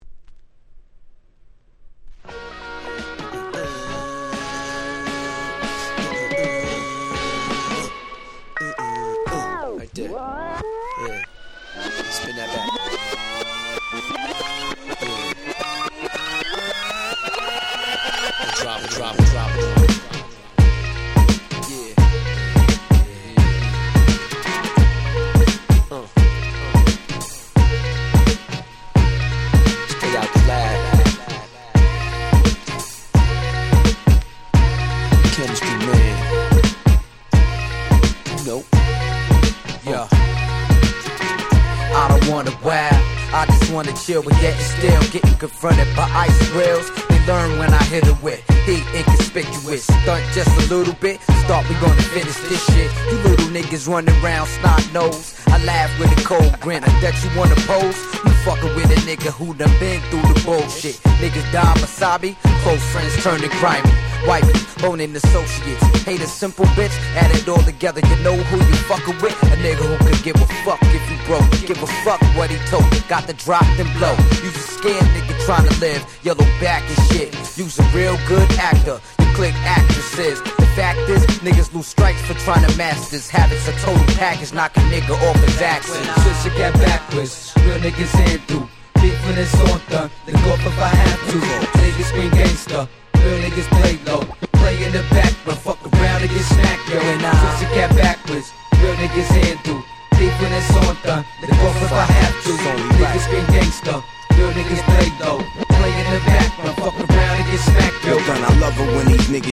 05' Nice Boom Bap / Hip Hop !!
ブーンバップ